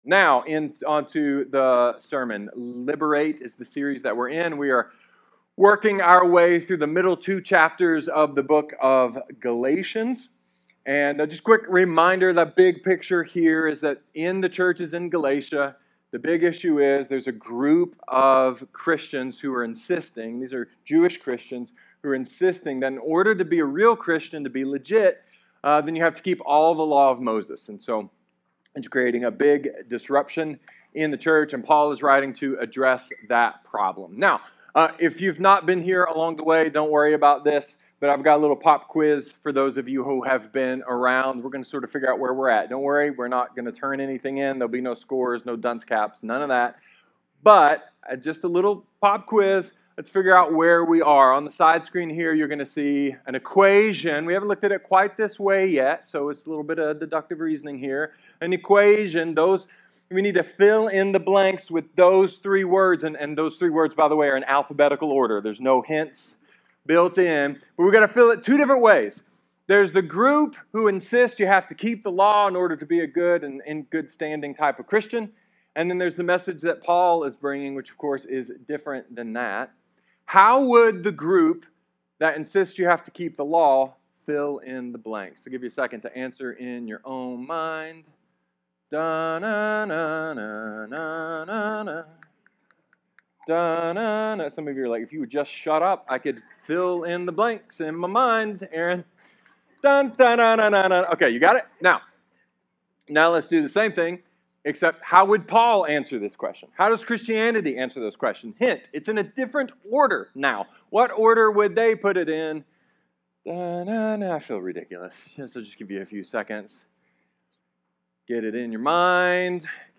A sermon with some nerdy things and some really simple things, that’s all about rediscovering the joy of religion (without the icky parts).